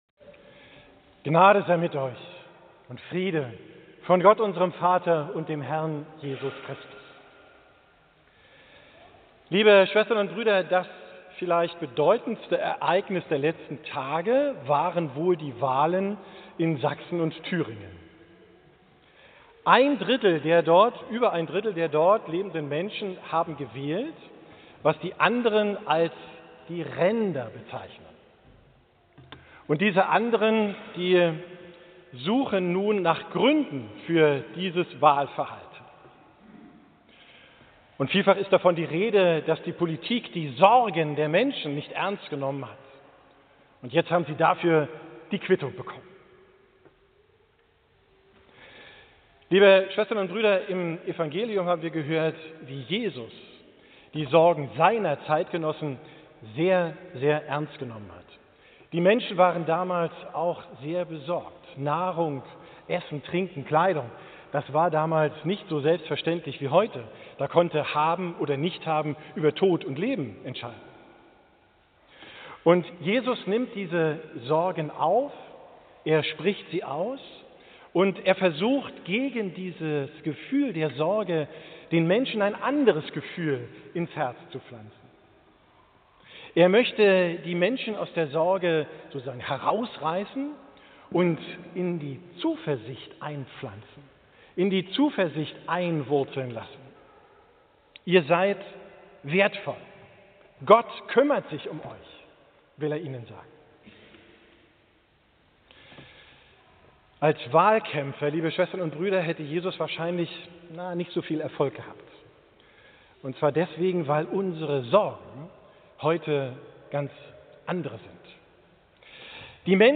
Predigt vom 15.